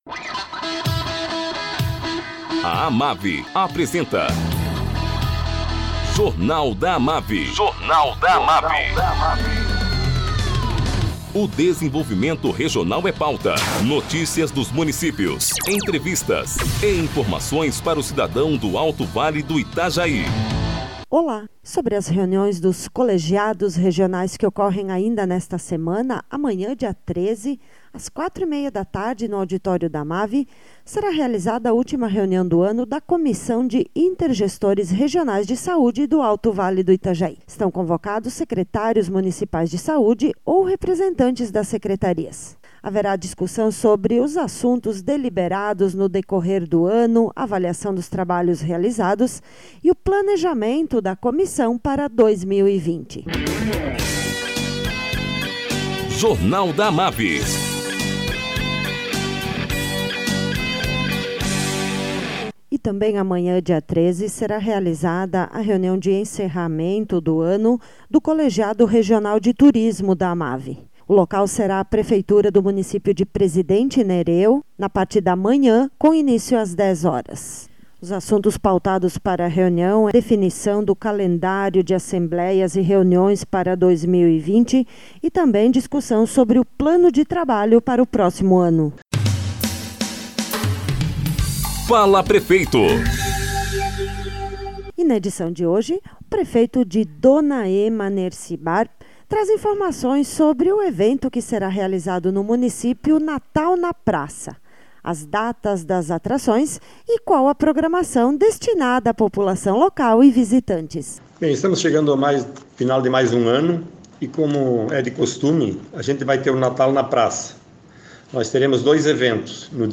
Prefeito de Dona Emma, Nerci Barp, fala sobre a programação do "Natal na Praça", que será realizado nos dias 20 e 22 de dezembro.